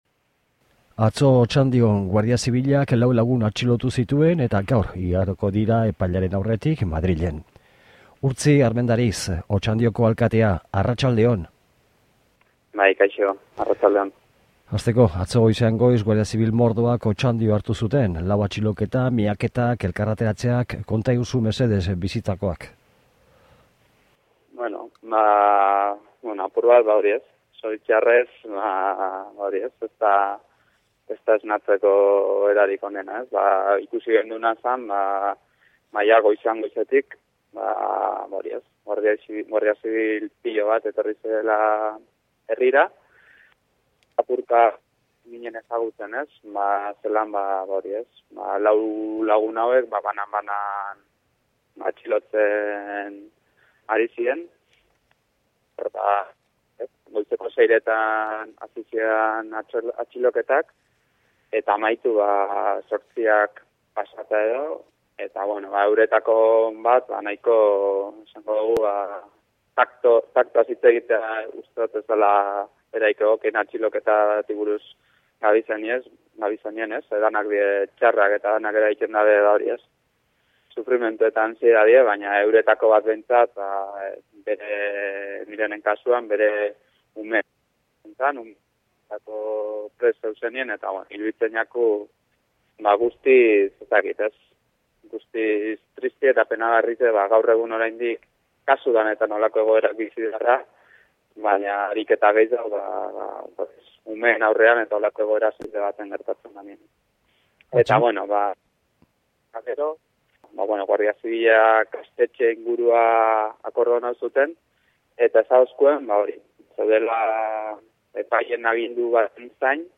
Otxandioko alkatearekin hitz egiten atxiloketen inguruan